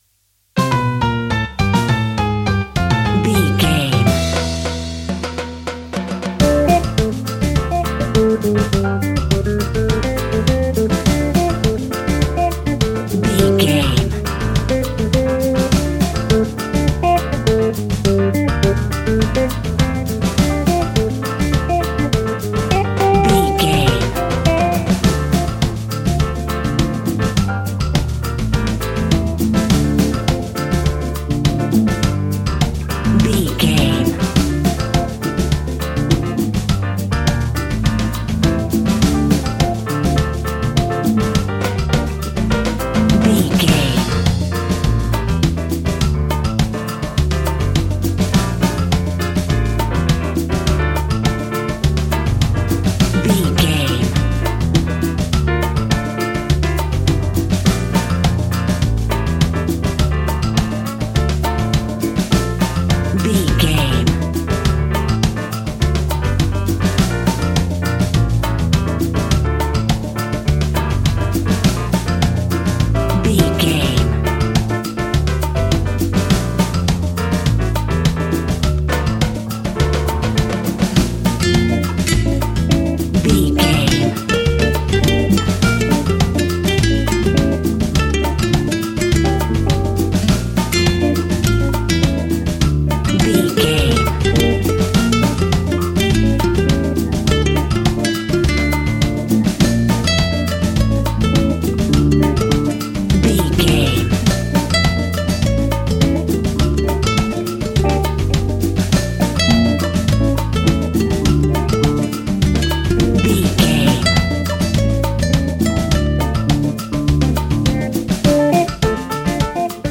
Aeolian/Minor
funky
energetic
romantic
percussion
electric guitar
acoustic guitar